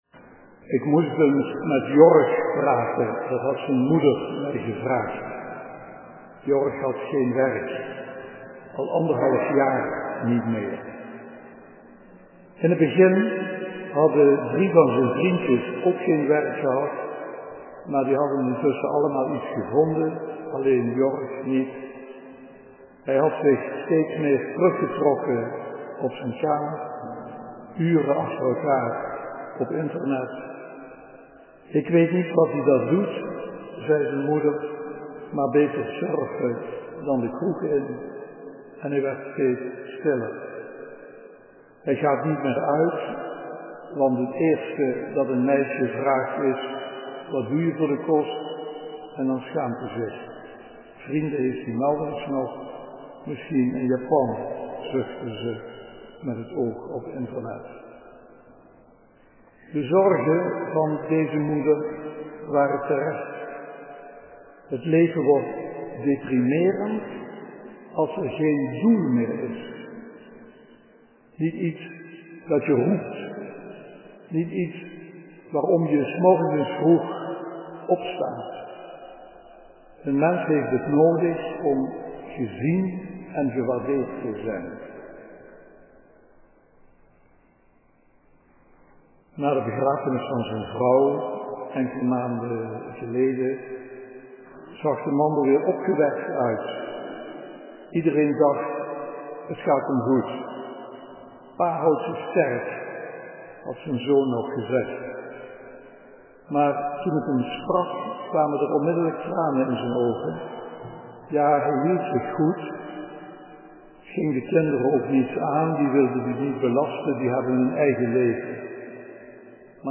De datum waarop de preek gehouden is ligt gewoonlijk een week later